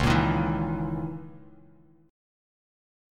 C#m6add9 chord